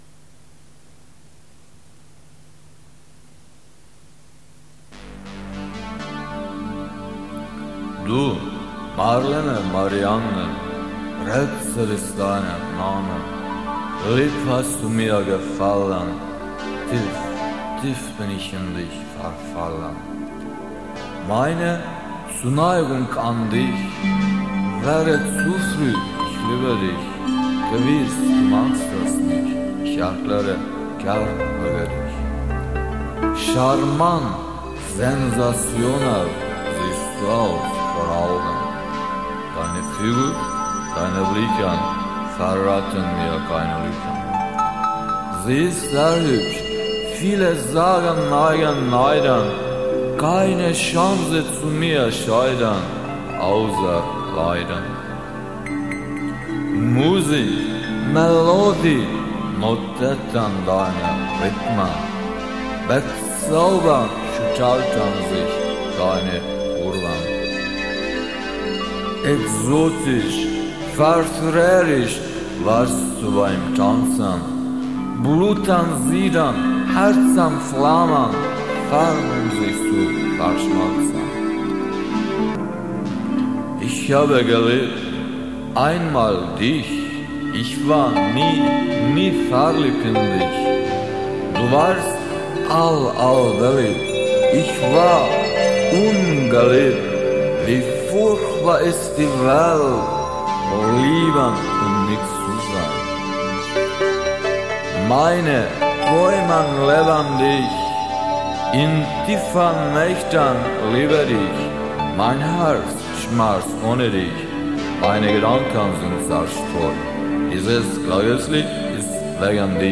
Hızlı ve yüksek sesli Pop-Rockt' tan (bağrı-çağrı).
Çalgı sesleri (Enstrümantal) genelde bilgisayar destekli
Hacimsel enstrümantal sesler arka plandan yansıtılarak